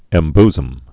(ĕm-bzəm, -bzəm)